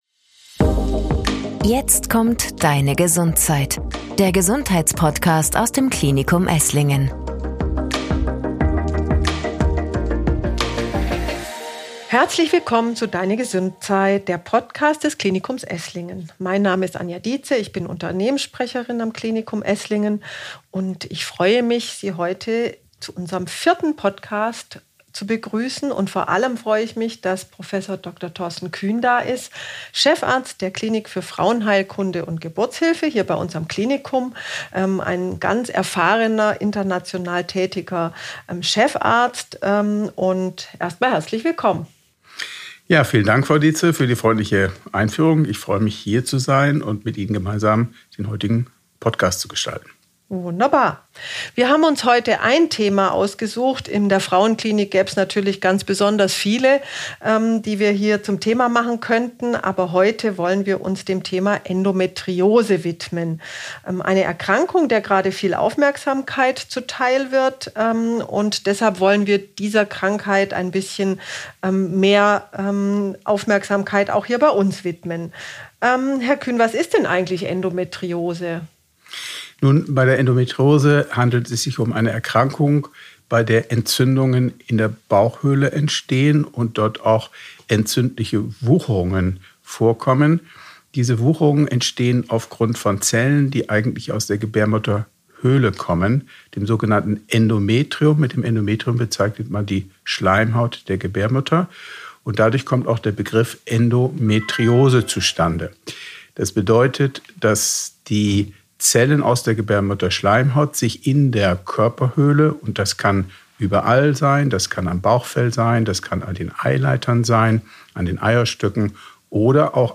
Mit Endometriose leben lernen - ein Gespräch